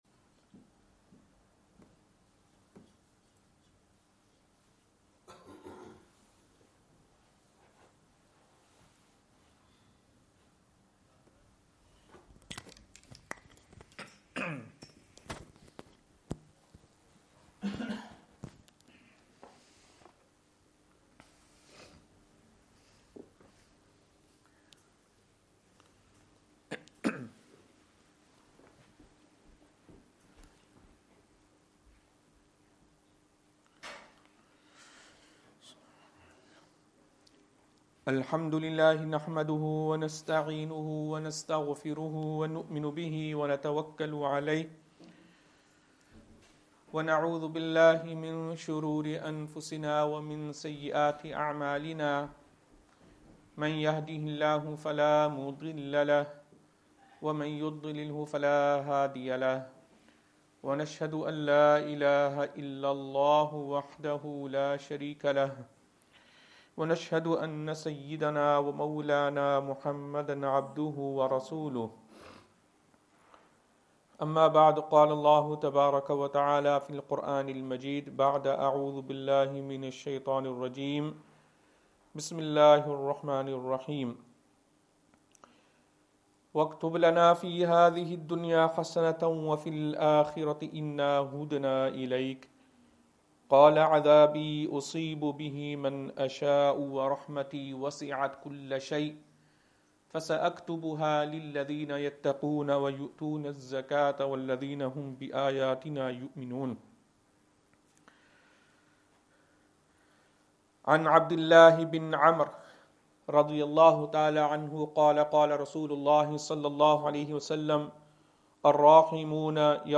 Jumah
Madni Masjid, Langside Road, Glasgow